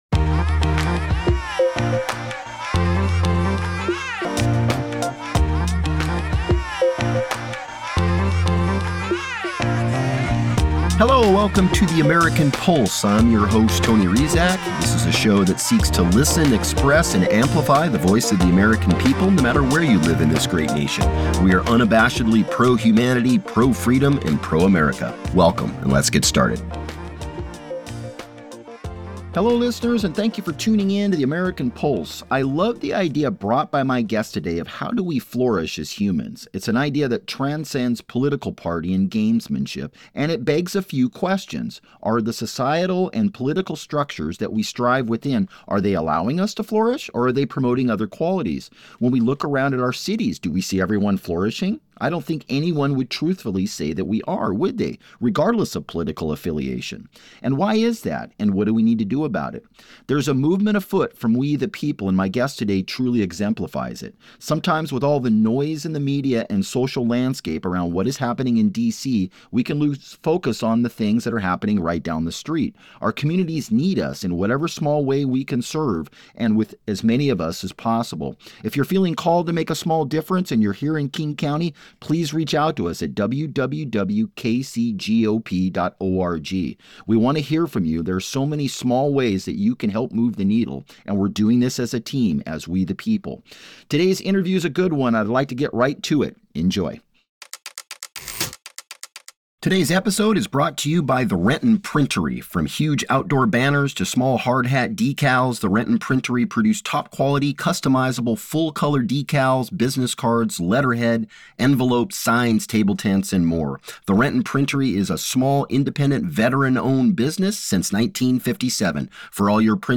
Join The American Pulse as we welcome citizen journalist and entrepreneur